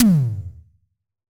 Tom_B2.wav